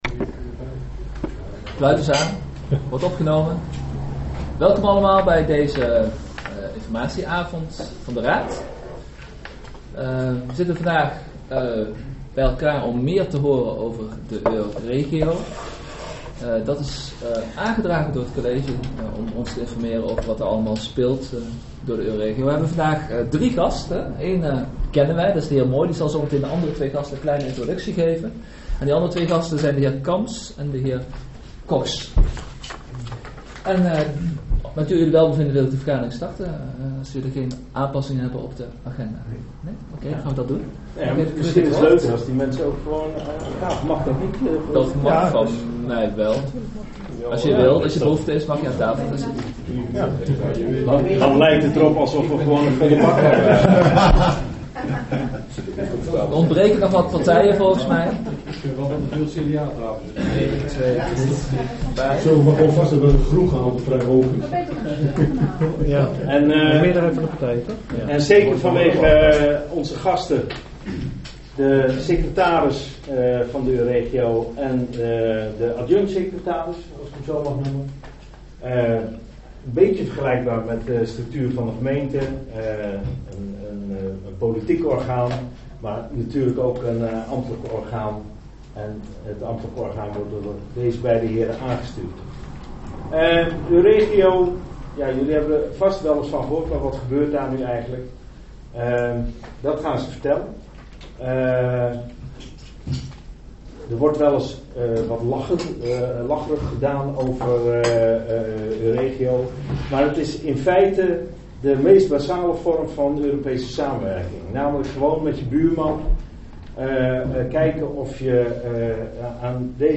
Locatie S02, gemeentehuis Elst
Informatiebijeenkomst Raad over Euregio